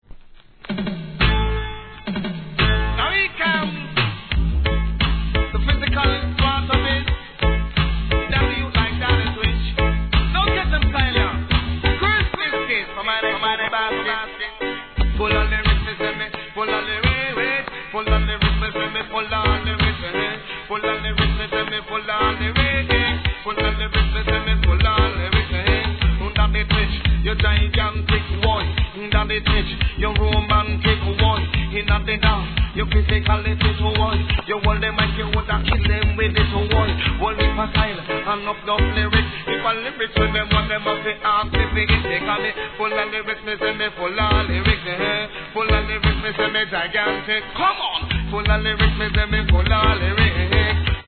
REGGAE
1988年人気DeeJayスタイル!!